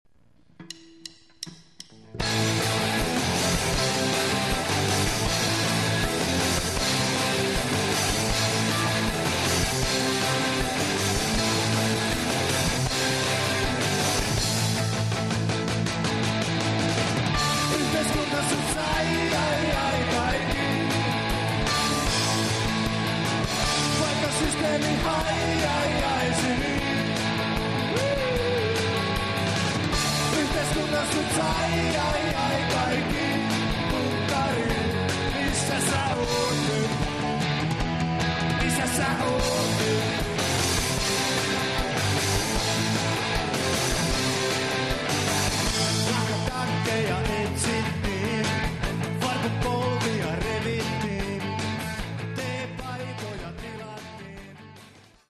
(Compressed/Mono 379KB)
KAIKKI ON TÄYSIN LIVENÄ ÄÄNNELTYÄ